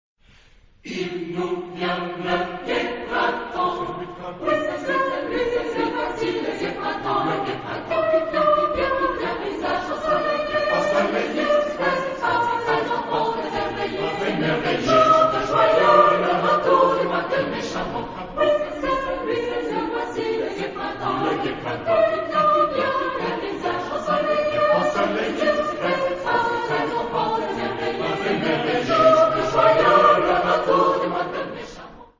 Genre-Style-Forme : Profane ; Fantaisie
Caractère de la pièce : exalté ; joyeux ; pastiche
Type de choeur : SATB  (4 voix mixtes )
Tonalité : si bémol majeur